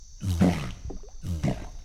Le rugissement d’un caïman noir adulte.
rugissement-adulte-caiman-noir.mp3